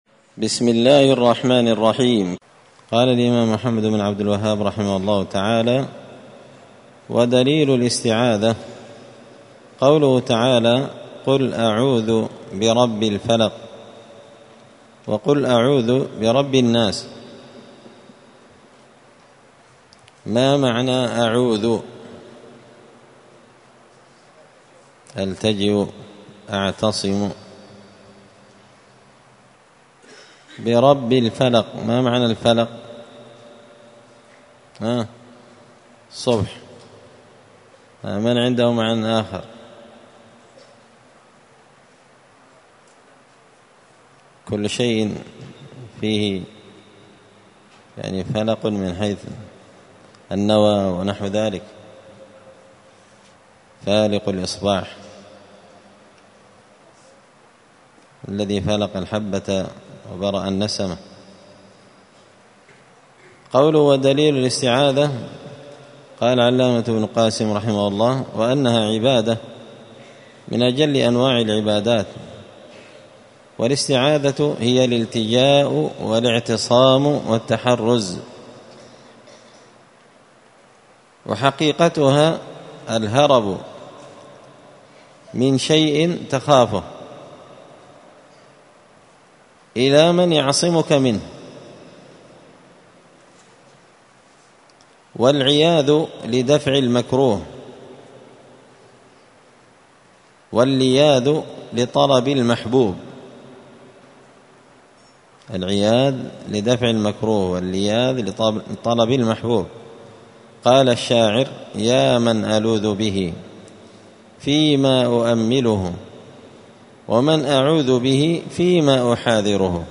مسجد الفرقان قشن_المهرة_اليمن
*الدرس السابع عشر (17) من قوله {ودليل الاستعاذة قوله تعالى(قل أعوذ برب الفلق @ وقل أعوذ برب الناس…}*